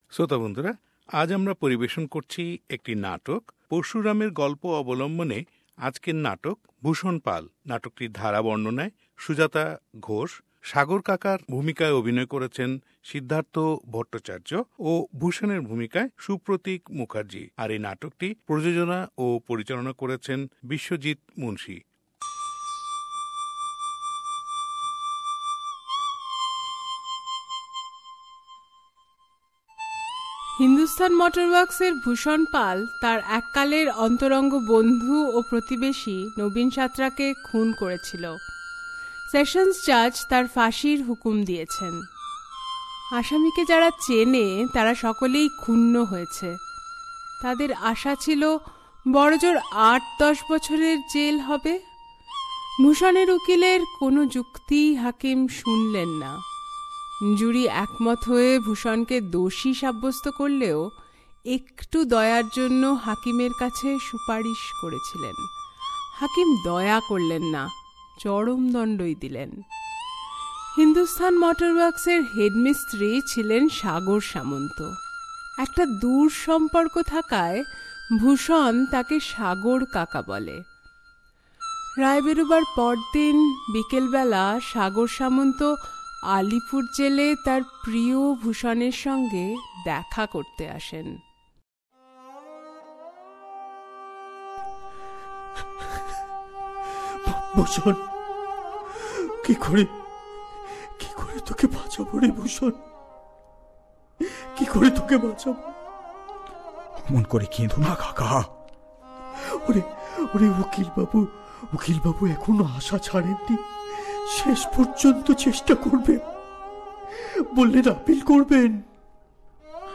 Drama : Bhusan Pal